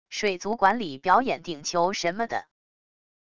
水族馆里表演顶球什么的wav音频